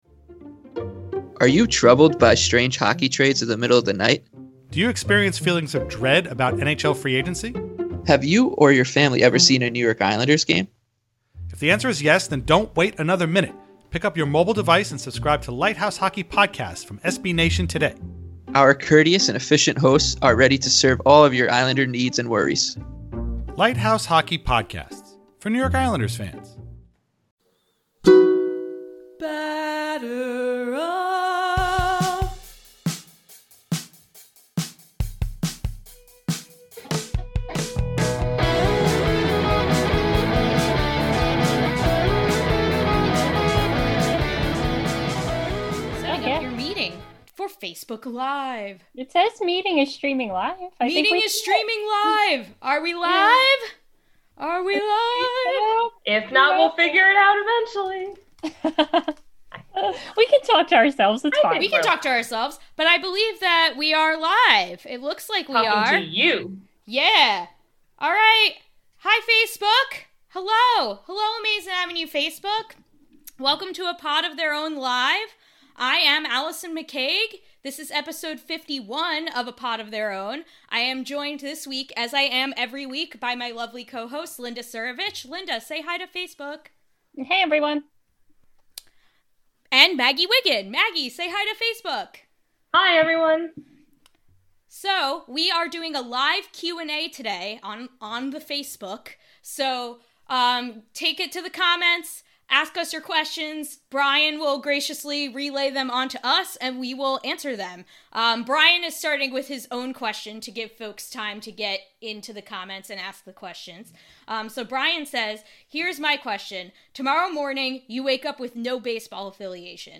Welcome back to A Pod of Their Own, a show by the women of Amazin’ Avenue where we talk all things Mets, social justice issues in baseball, and normalize female voices in the sports podcasting space.
This week, we took to Facebook Live to tackle whatever burning questions listeners could throw at us. Minor leagues, Rob Manfred, the state of LGBTQ issues in baseball, our reading lists, this pod has a little bit of everything.